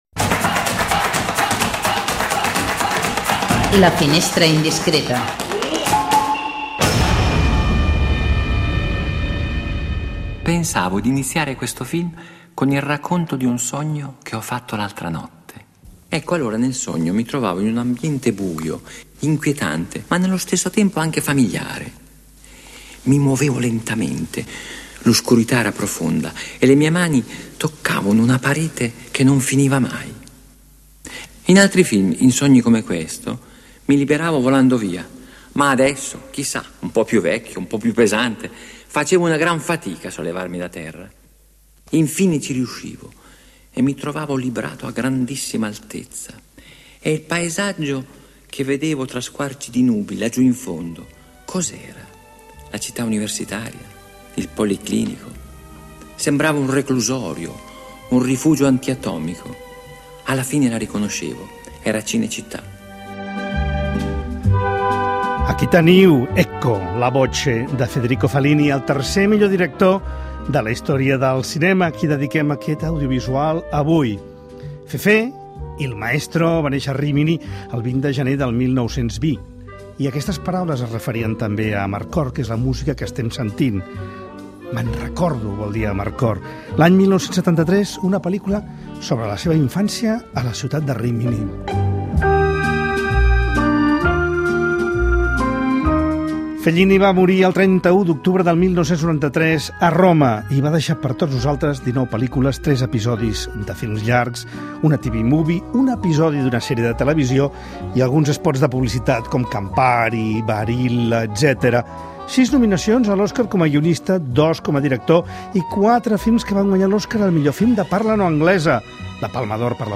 Un viatge musical per un dels grans directors de la hist�ria del cinema.